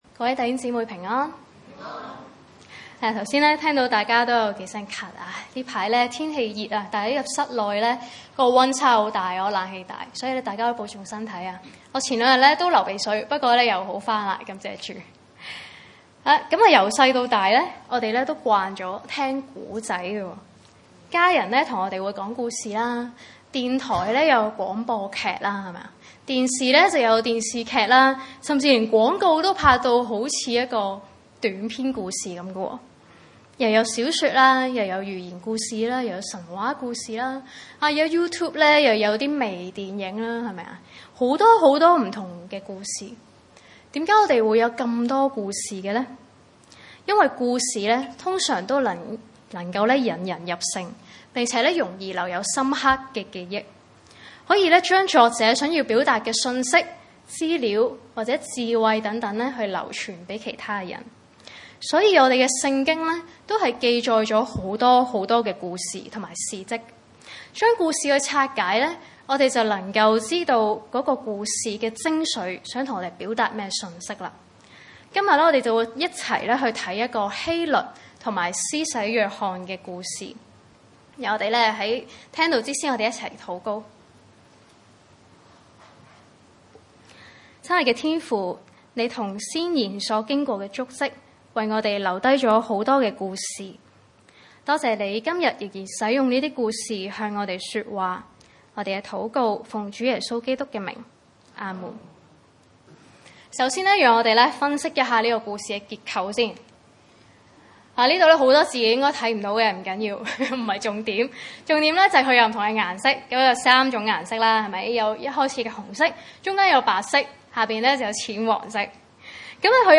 經文: 馬可福音 6 : 14 - 29 崇拜類別: 主日午堂崇拜 14.